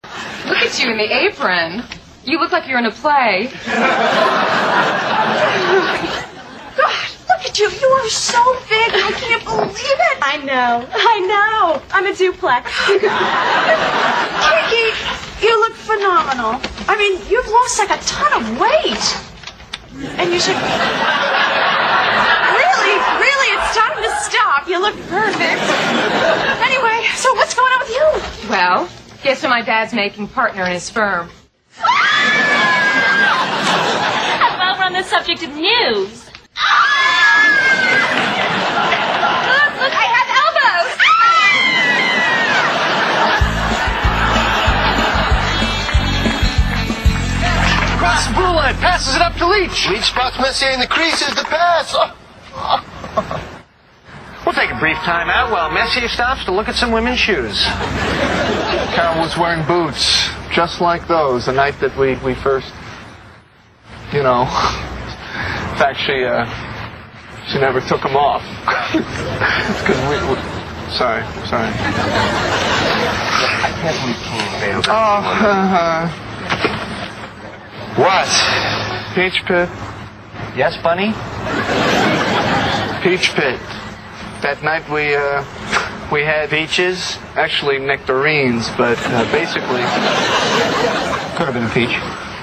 在线英语听力室老友记精校版第1季 第36期:克林顿亲信助手(4)的听力文件下载, 《老友记精校版》是美国乃至全世界最受欢迎的情景喜剧，一共拍摄了10季，以其幽默的对白和与现实生活的贴近吸引了无数的观众，精校版栏目搭配高音质音频与同步双语字幕，是练习提升英语听力水平，积累英语知识的好帮手。